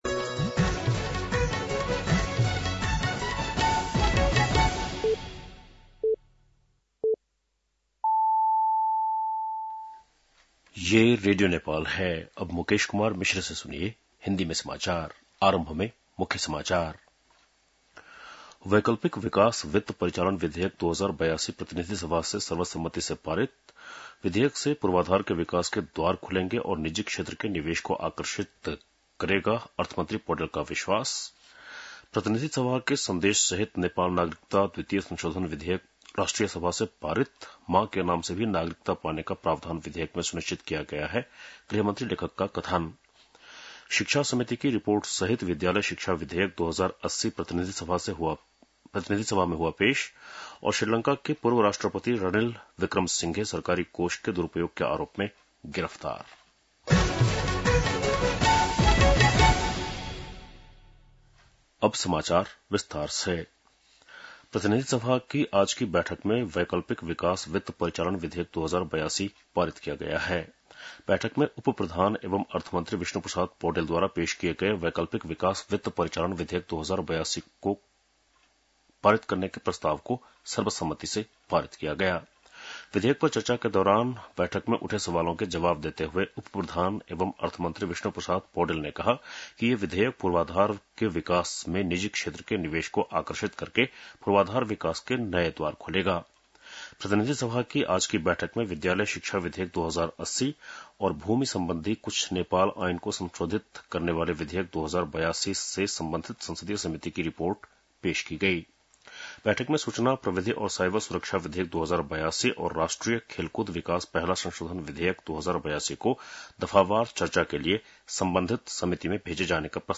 बेलुकी १० बजेको हिन्दी समाचार : ६ भदौ , २०८२